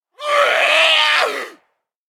DayZ-Epoch / SQF / dayz_sfx / zombie / chase_0.ogg
chase_0.ogg